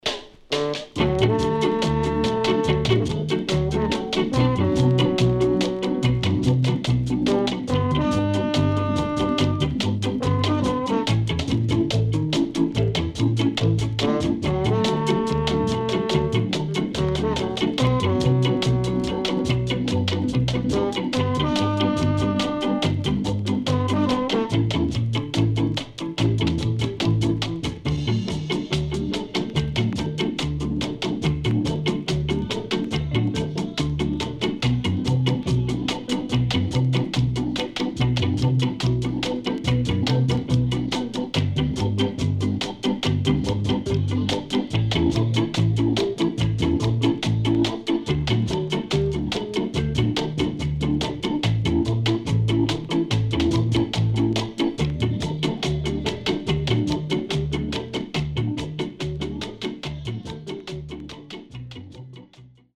EARLY REGGAE
CONDITION SIDE A:VG(OK)〜VG+
SIDE A:うすいこまかい傷ありますがノイズあまり目立ちません。